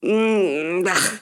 Reacción de asco de una mujer: ¡Puag!
exclamación
interjección
mujer
repugnancia
Sonidos: Voz humana